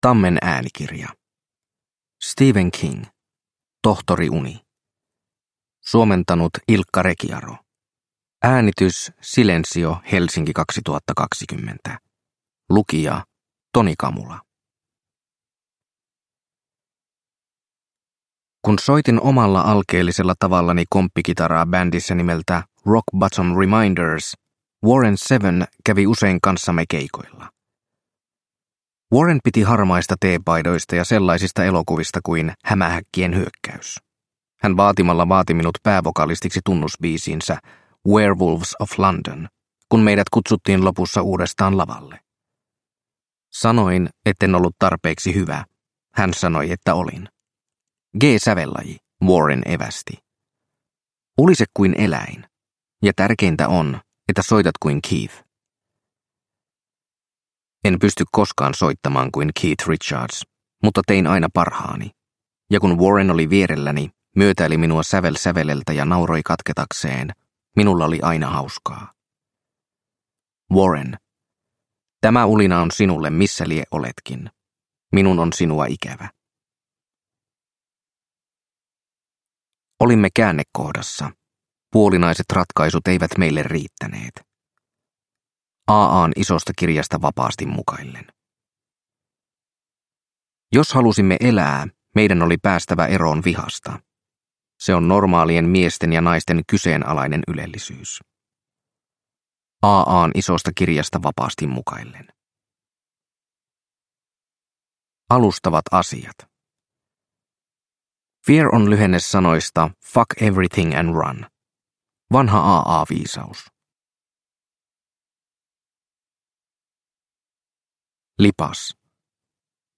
Tohtori Uni – Ljudbok – Laddas ner